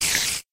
spider2.ogg